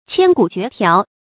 千古絕調 注音： ㄑㄧㄢ ㄍㄨˇ ㄐㄩㄝˊ ㄉㄧㄠˋ 讀音讀法： 意思解釋： 猶千古絕唱。